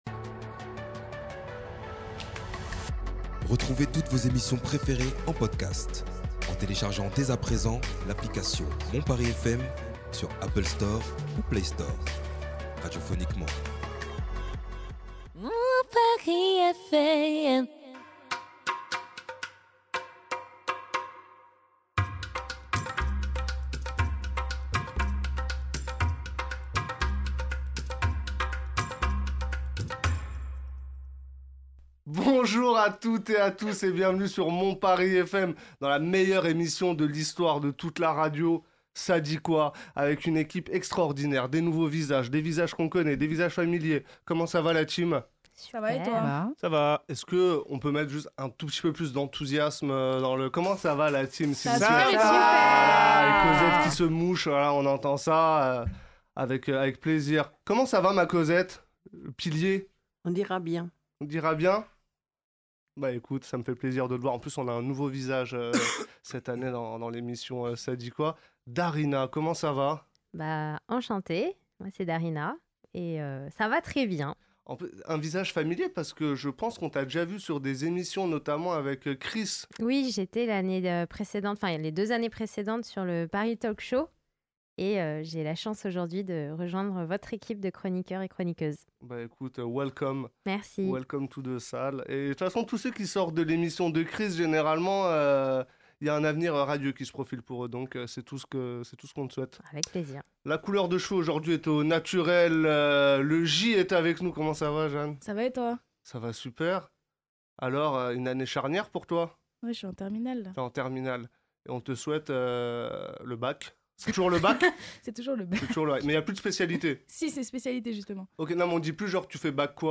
Débat de la semaine